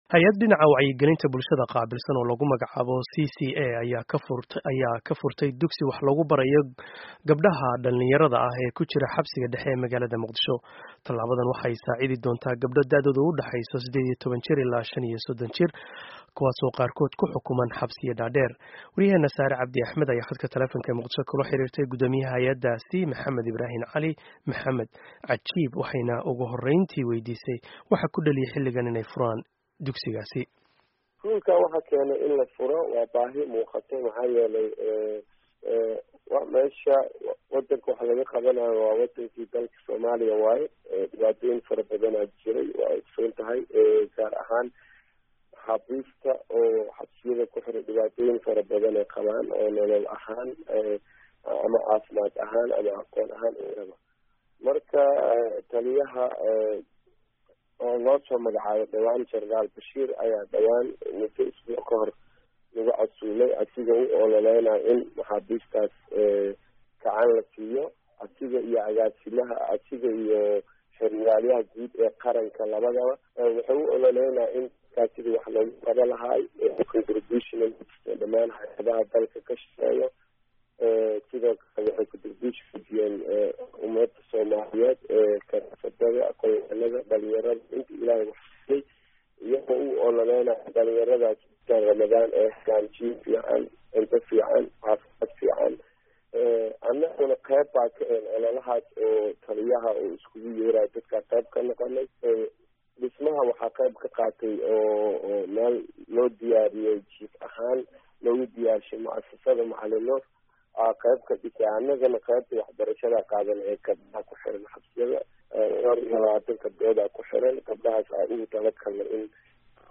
Dhegayso: Waraysi ku saabsan Tababarka Haweenka Maxaabiista ah